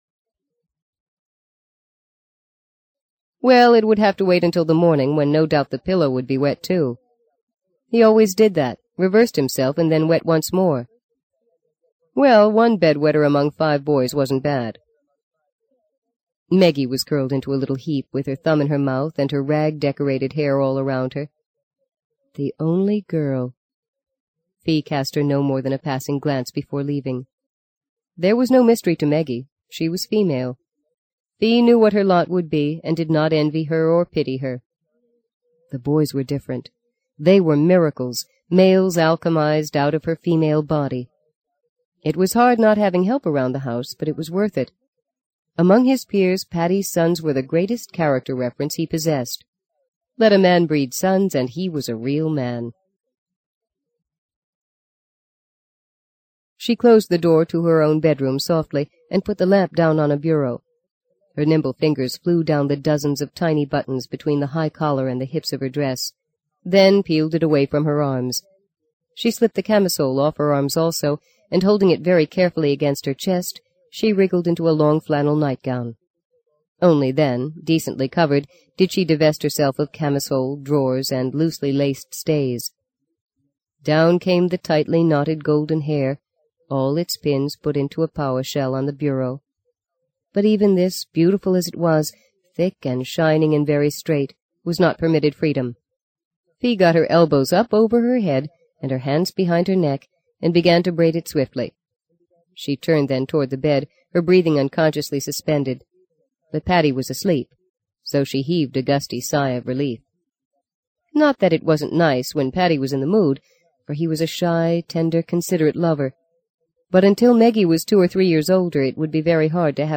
在线英语听力室【荆棘鸟】第一章 11的听力文件下载,荆棘鸟—双语有声读物—听力教程—英语听力—在线英语听力室